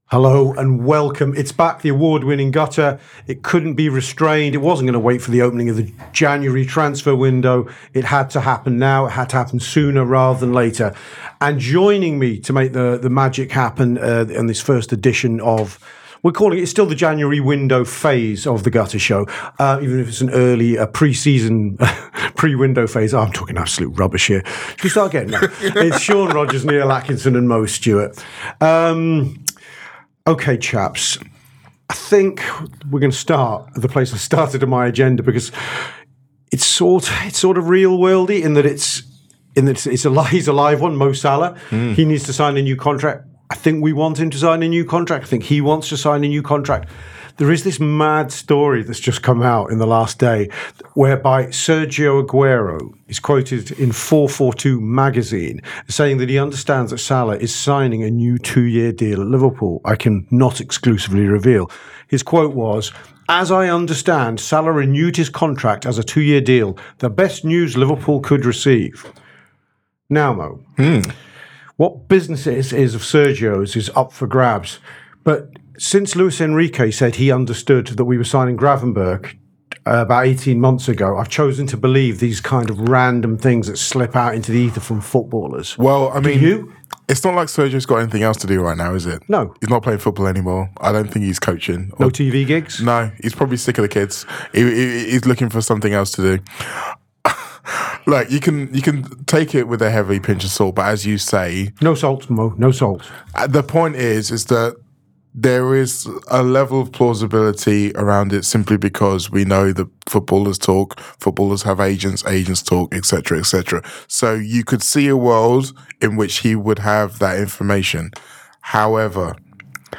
Below is a clip from the show – subscribe for more on Liverpool’s January planning and new contracts..